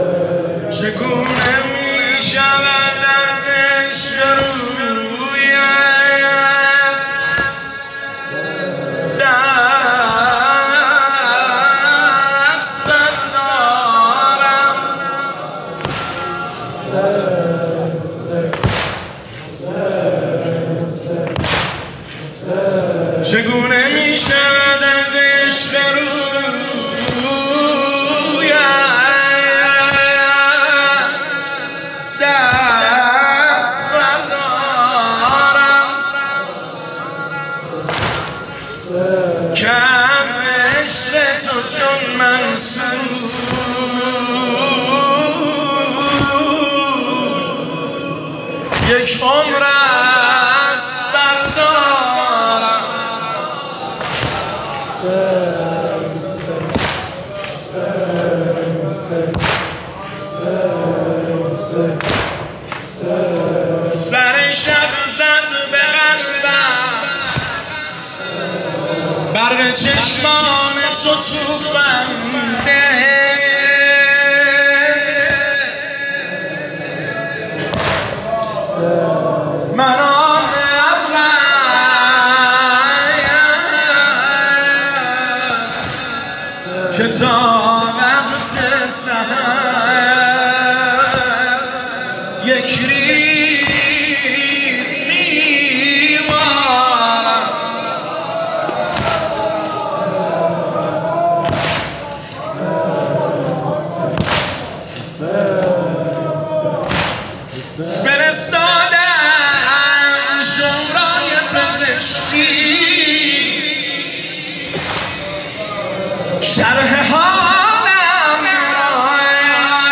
هیئت بین الحرمین طهران
دانلود (صوت ضبط شده)